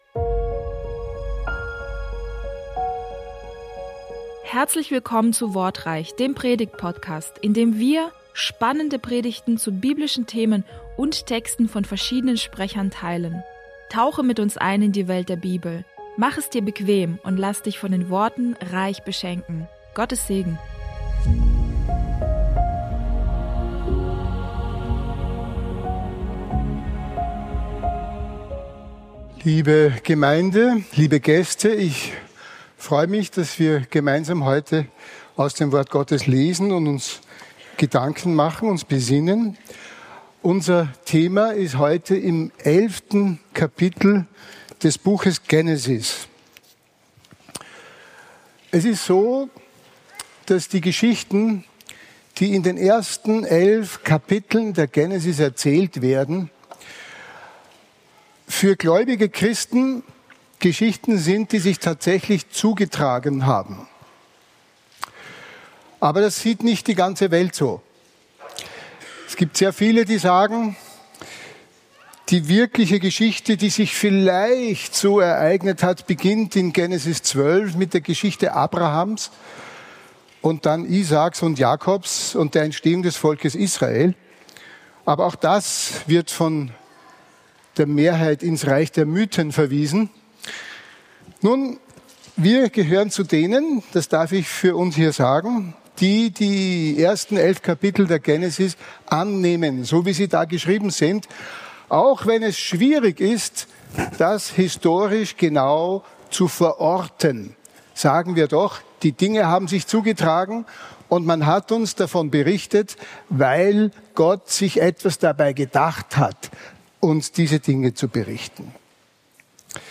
Wortreich - Der Predigtpodcast